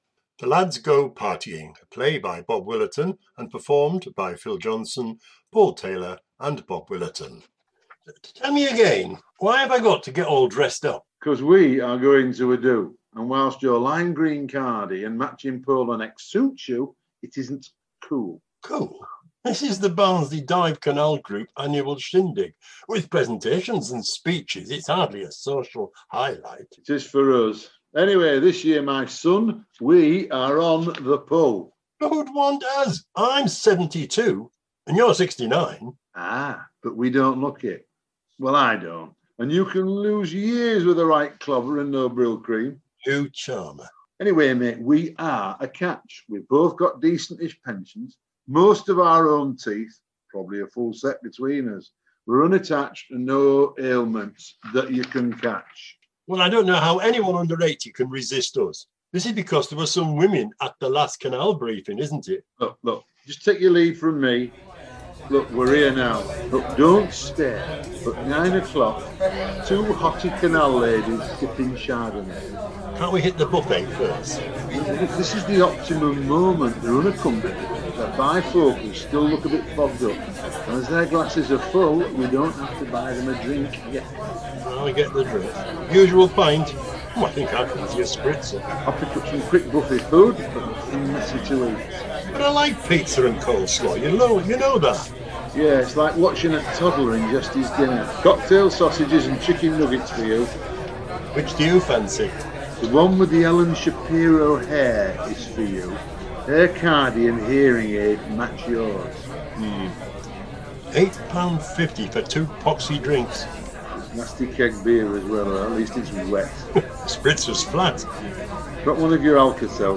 A short play